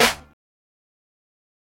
SouthSide Snare (34).wav